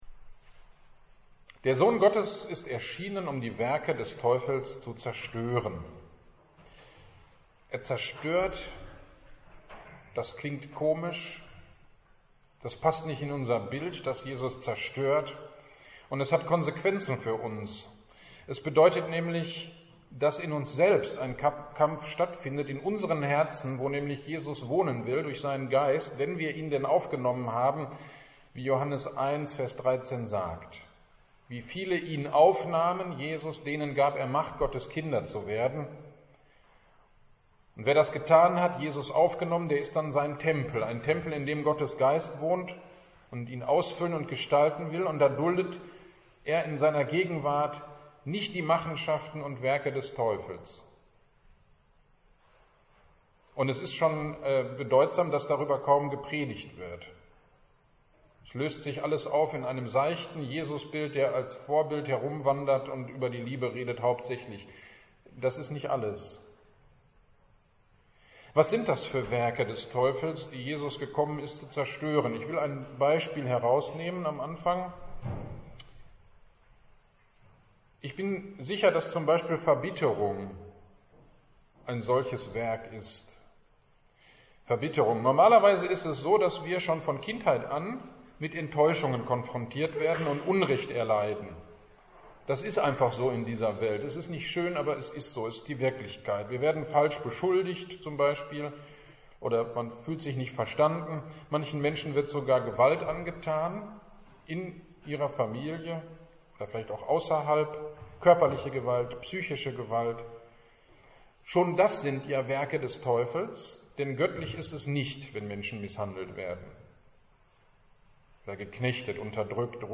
Predigt am 21.02.21 (Invokavit) zu 1. Johannes 3,8 - Kirchgemeinde Pölzig